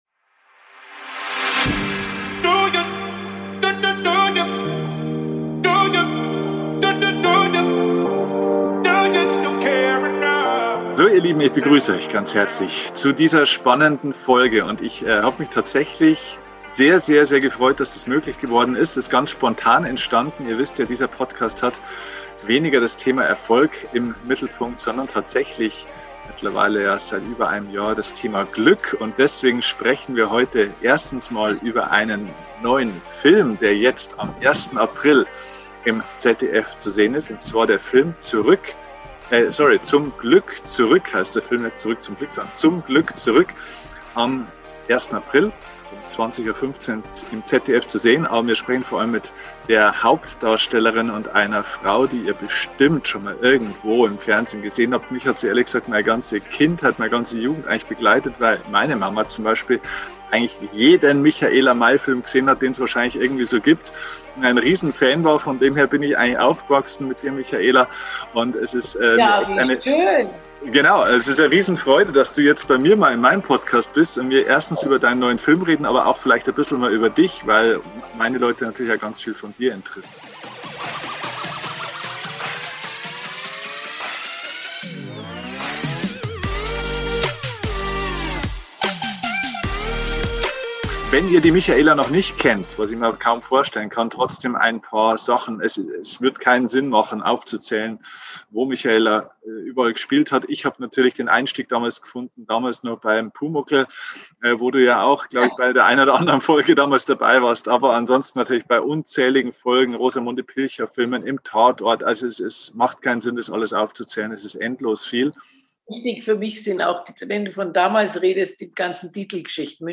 #382 Zum Glück zurück – Interview mit Schauspielerin Michaela May | Glück im Leben | Schauspiel | Affirmation ~ DIE KUNST ZU LEBEN - Dein Podcast für Lebensglück, moderne Spiritualität, emotionale Freiheit und berufliche Erfüllung Podcast